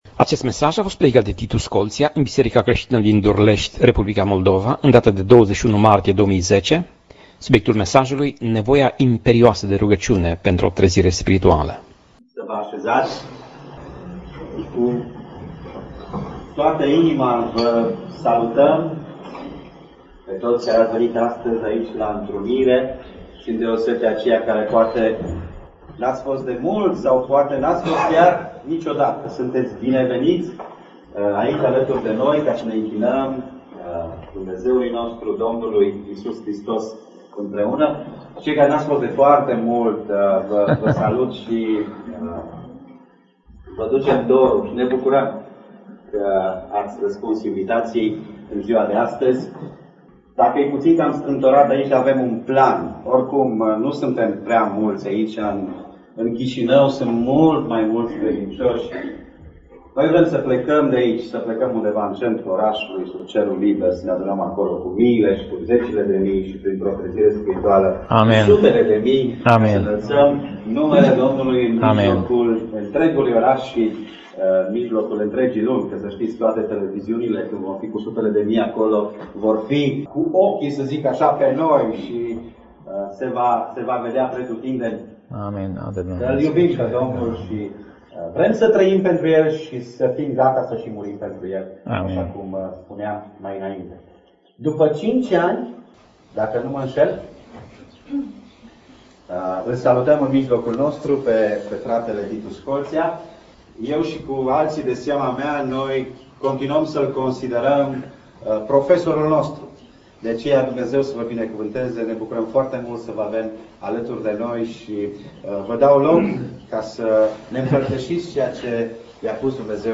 Pasaj Biblie: 2 Cronici 7:12 - 2 Cronici 7:16 Tip Mesaj: Predica